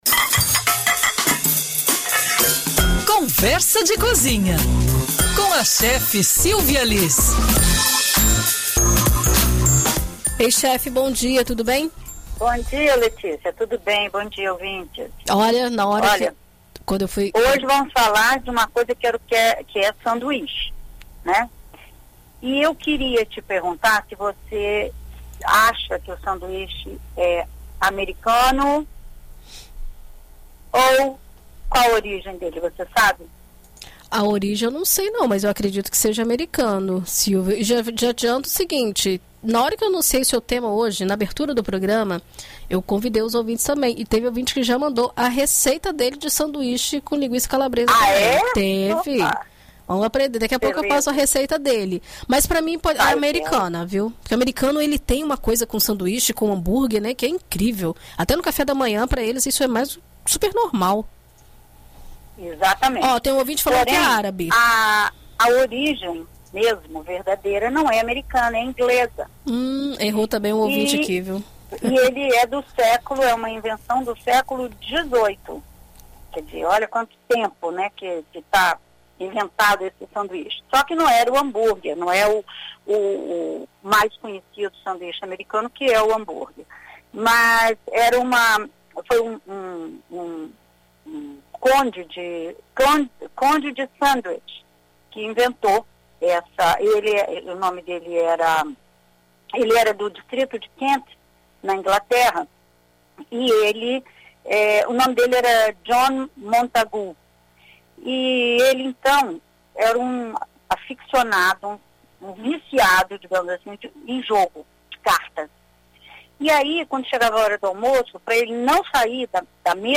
na BandNews FM Espírito Santo